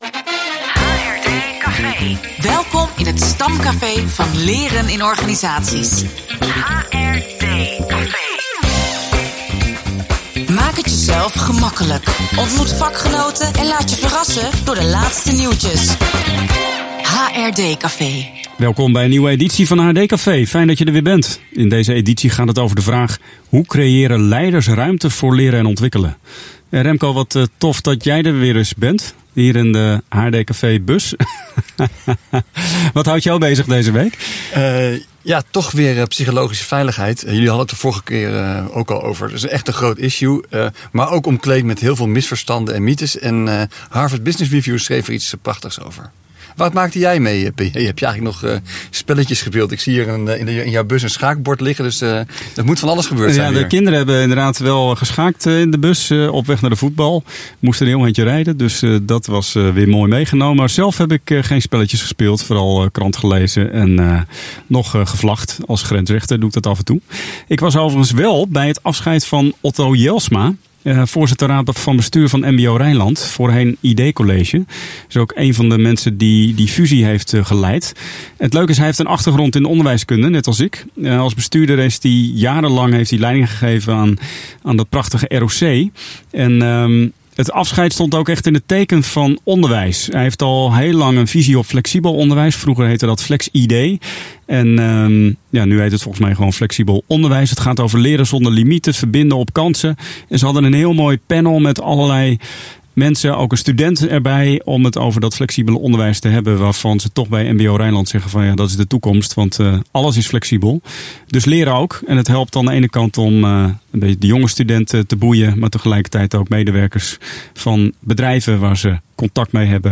Muzikale uitsmijter: een feestelijk fragment uit een live performance van de K&S-band!